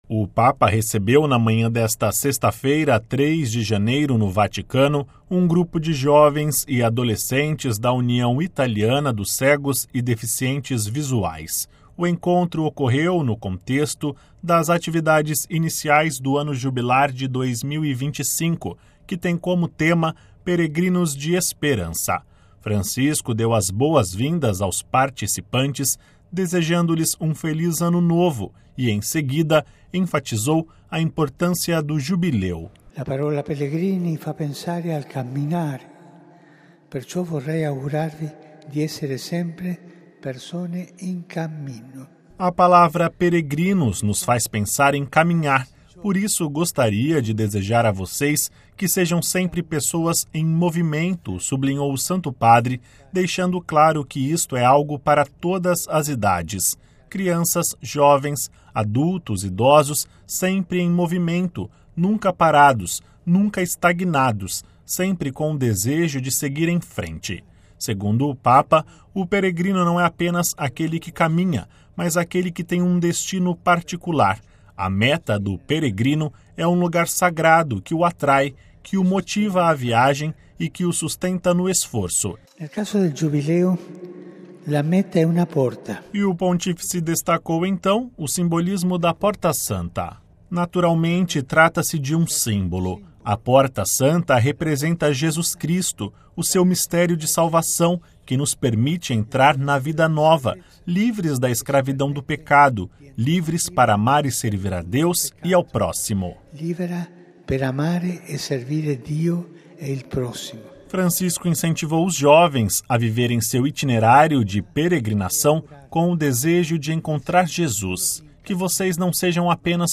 Ouça com a voz do Papa e compartilhe